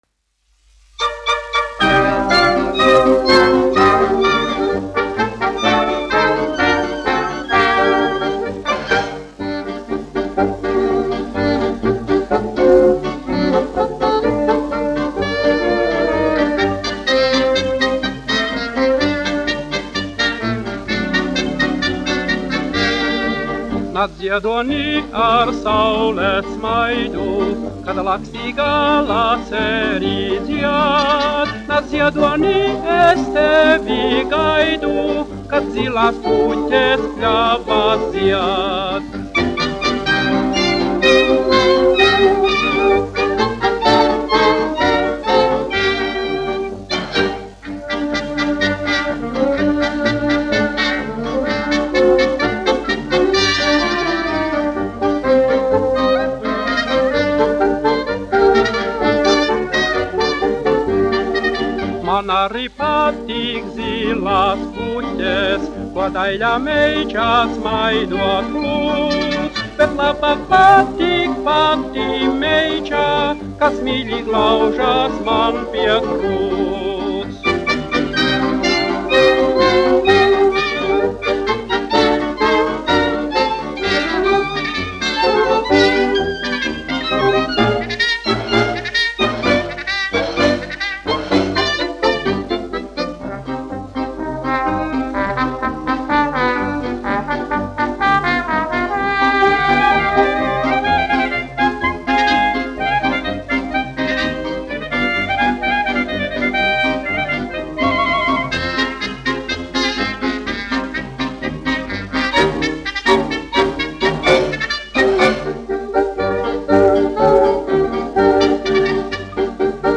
1 skpl. : analogs, 78 apgr/min, mono ; 25 cm
Fokstroti
Populārā mūzika -- Latvija
Skaņuplate